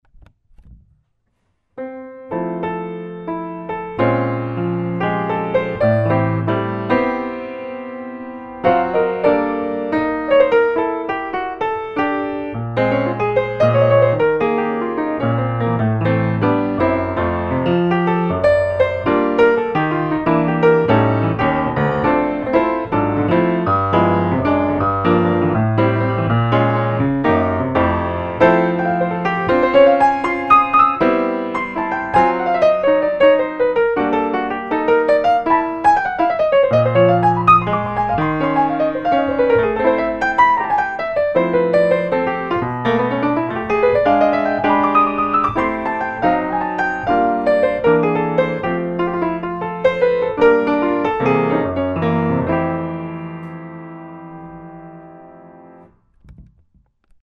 la troisième enrichi un peu plus et y ajoute le tempo